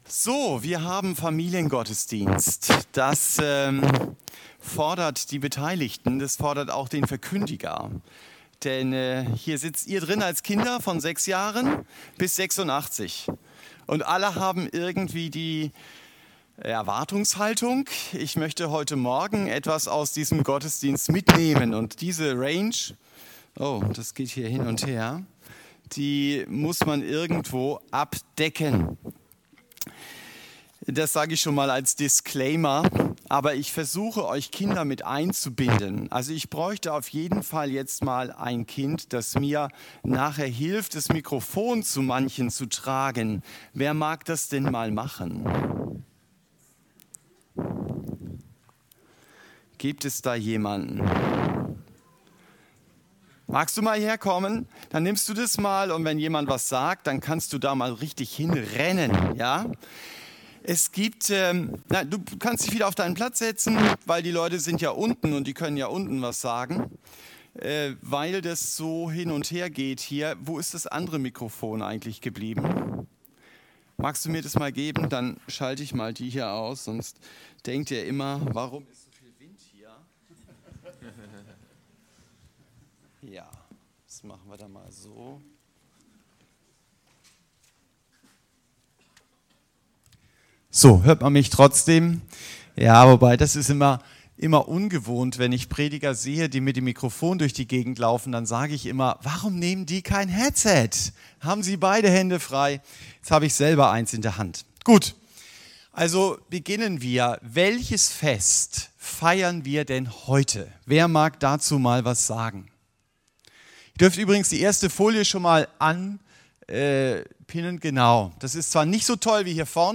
Erntedankgottesdienst 2023
Erntedankgottesdienst_2023_TP.mp3